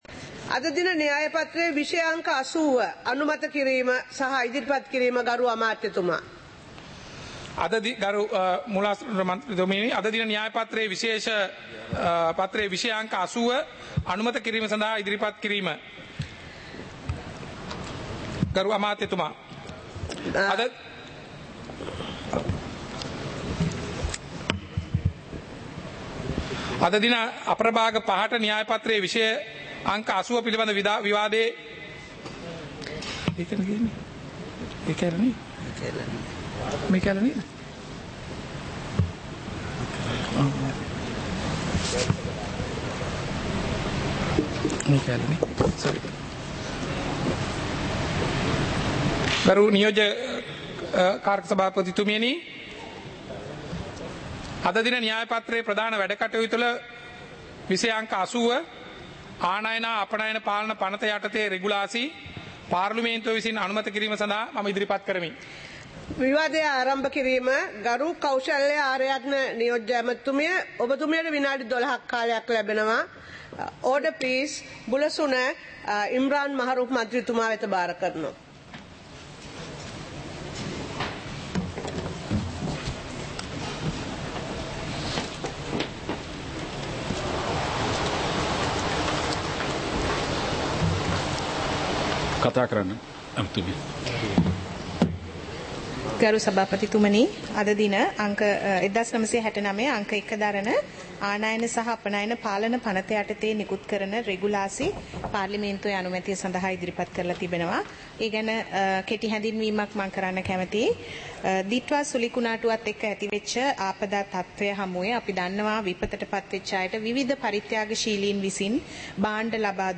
சபை நடவடிக்கைமுறை (2026-01-09)